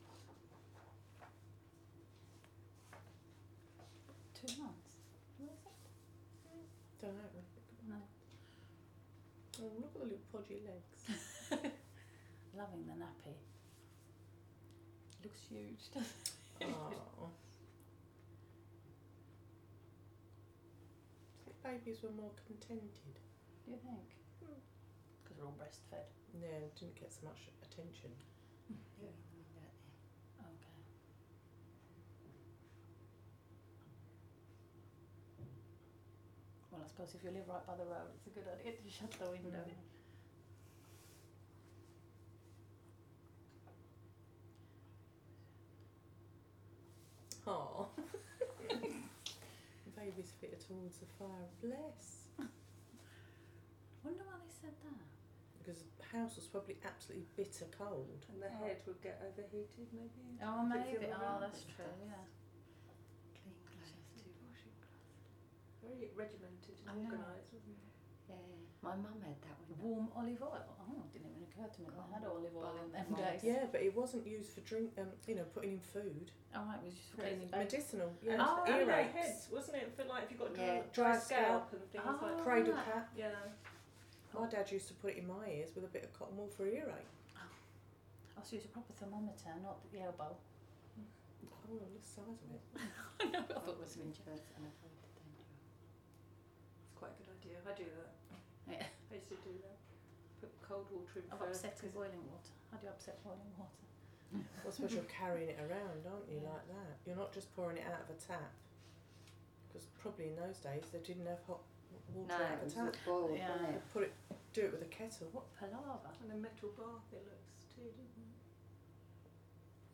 Interviews 4
An interview featuring a group of women and the recordist responding and reacting to the silent film, Bathing & Dressing, Parts 1 & 2. A very candid discussion of memories of motherhood follows the viewing of the film.